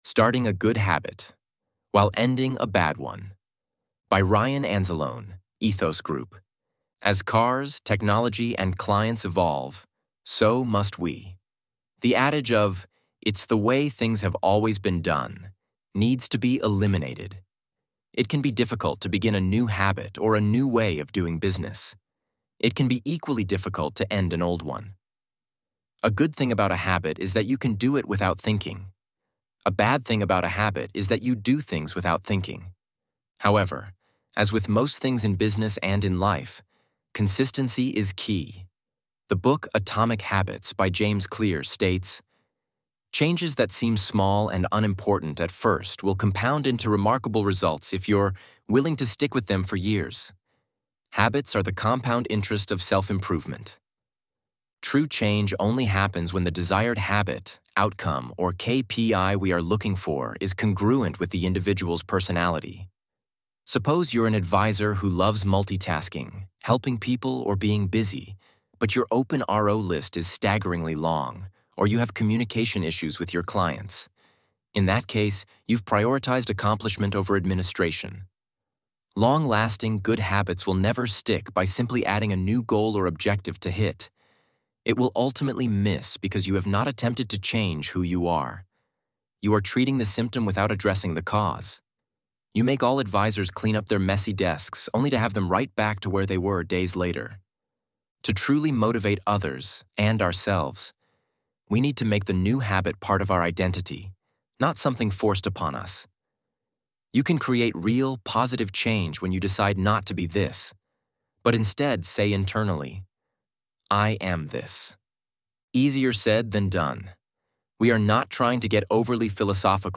ElevenLabs_Starting_a_Good_Habit_While_Ending_a_Bad_One.mp3